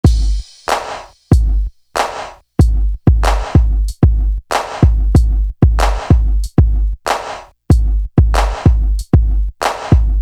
Marathon Drum.wav